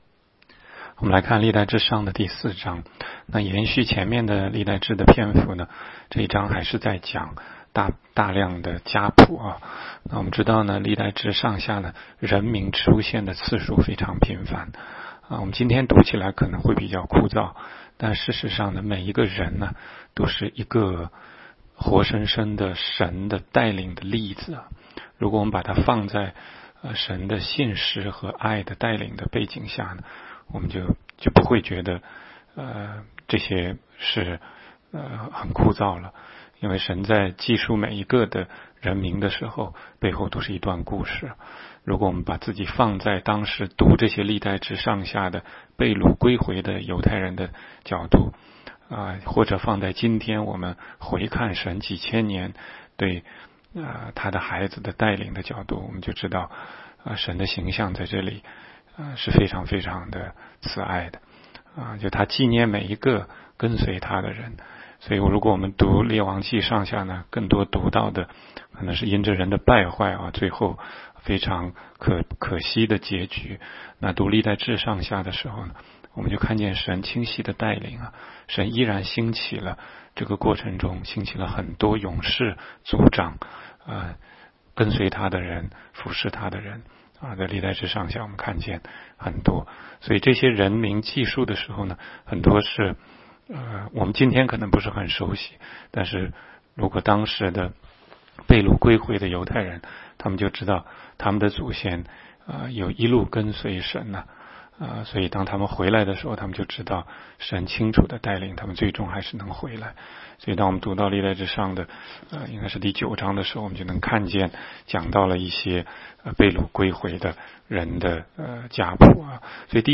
16街讲道录音 - 每日读经-《历代志上》4章
每日读经